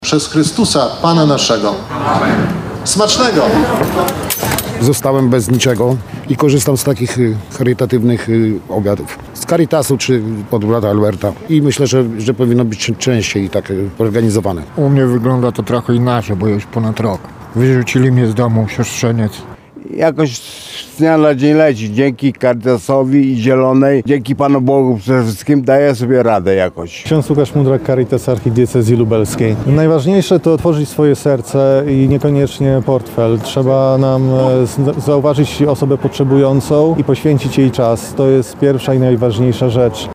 Spotkanie i wspólny posiłek odbył się na placu przed Archikatedrą Lubelską.